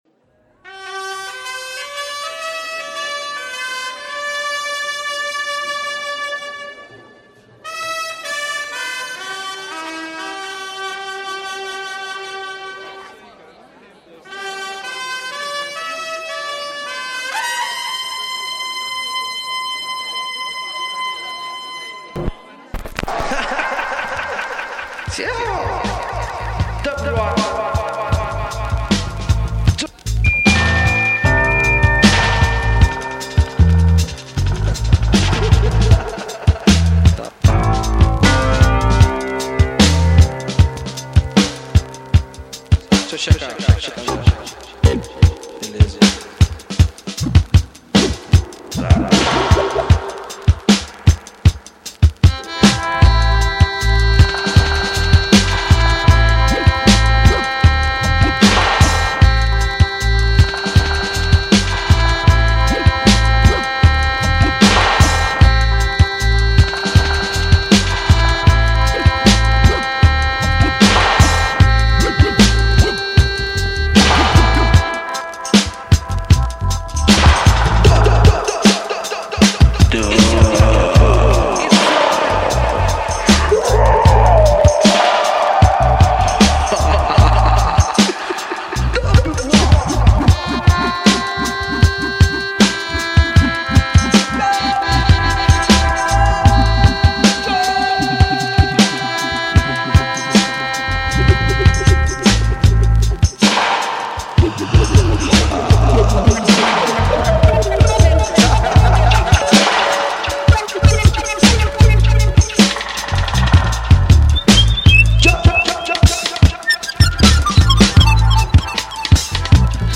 dub album in the purest jamaican...brasilian tradition !
various styles (reggae, afrobeat, dancehall, samba)